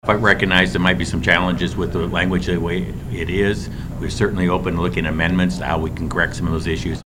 Representative Michael Bergan, a Republican from Dorchester who’s an accountant, says the bill as currently written could endanger federal funding for state government agencies.